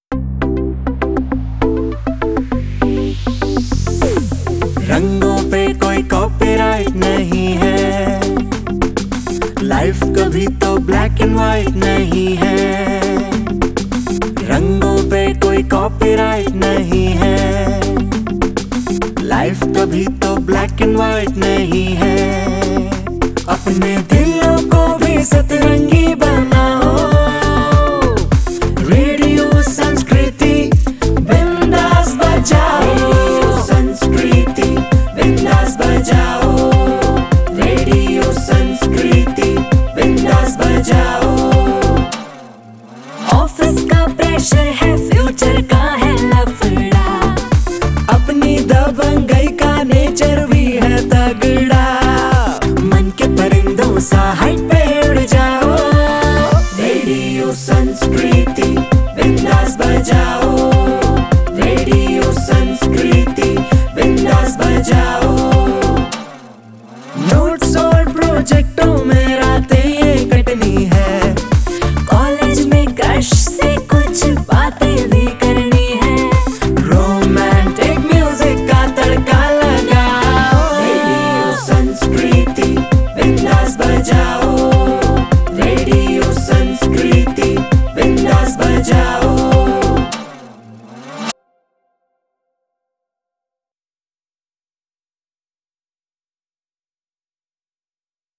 Jingle 1